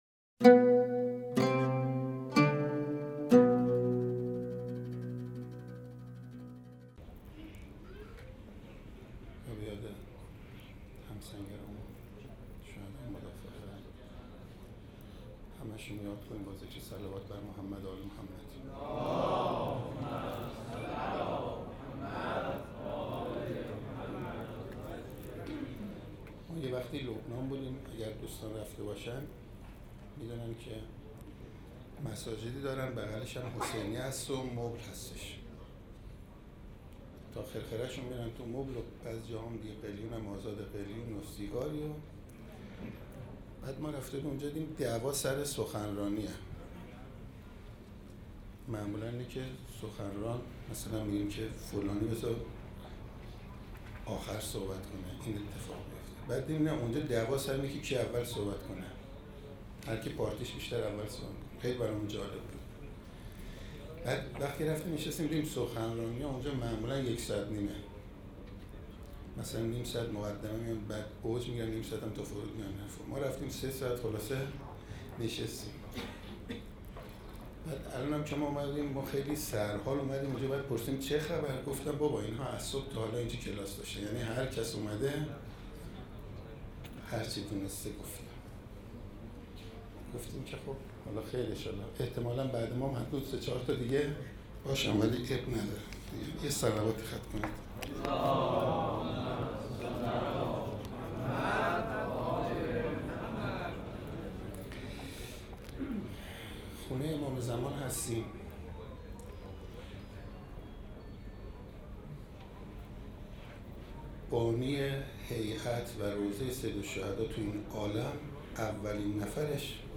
روایتگری
ششمین همایش هیأت‌های محوری و برگزیده کشور | شهر مقدس قم - مجتمع یاوران مهدی (عج)